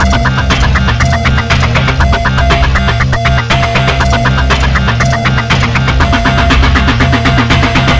industrial.wav